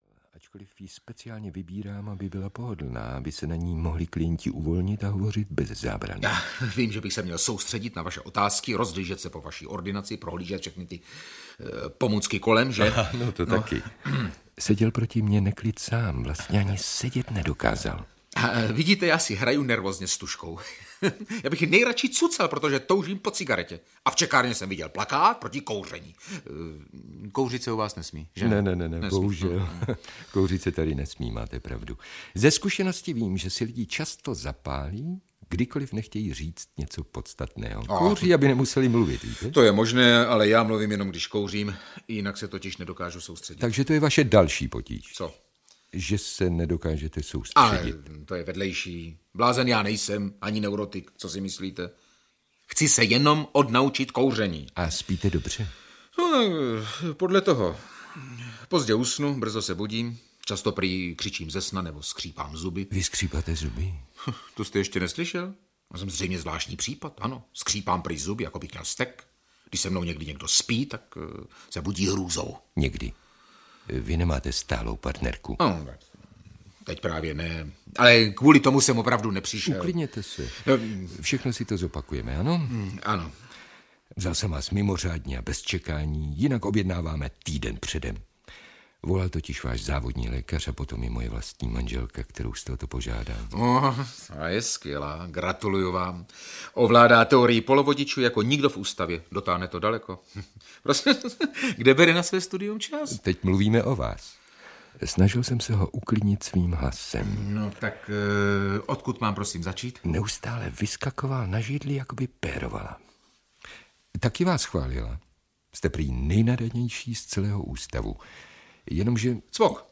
Ukradené fantazie audiokniha
Ukázka z knihy
• InterpretJiří Adamíra, Luděk Munzar, Jana Hlaváčová, Hana Maciuchová, Jaroslava Adamová, Jana Štěpánková